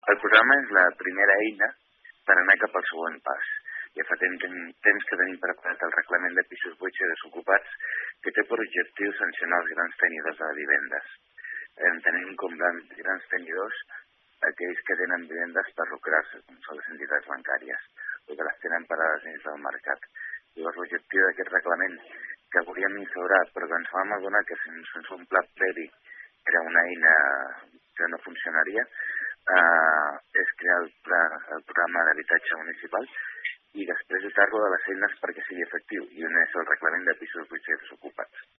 El regidor explica que el Programa d’Actuació Municipal d’Habitatge és una primera eina per poder tirar endavant el Reglament de Pisos Buits i Desocupats, amb el qual es podran sancionar a les entitats bancàries que tinguin pisos buits.